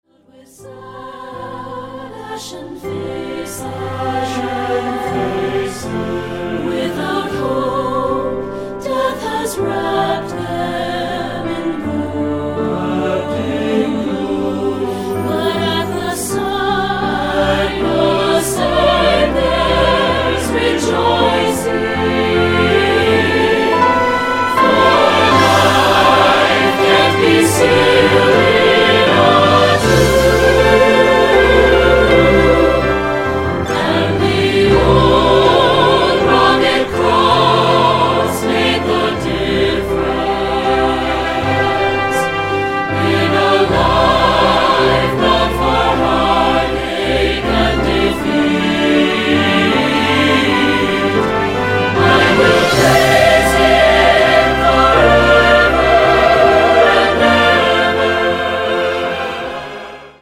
Voicing: Orchestral Score and Parts